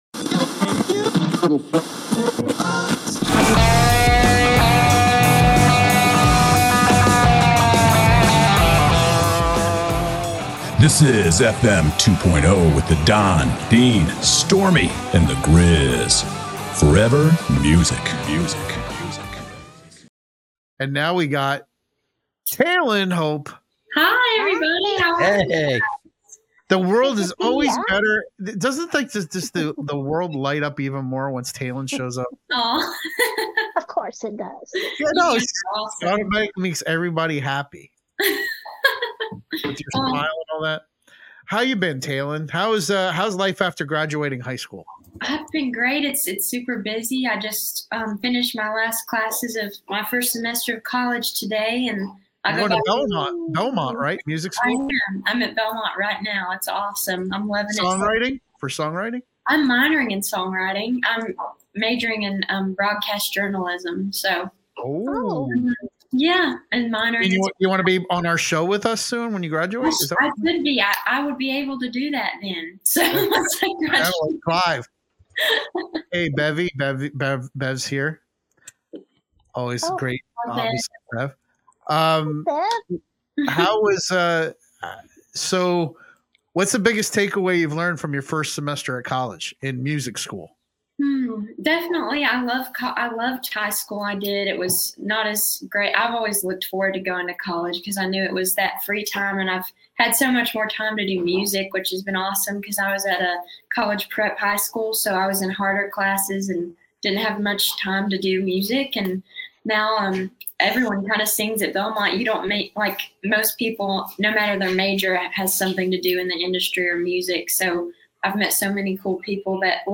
Singer Songwriter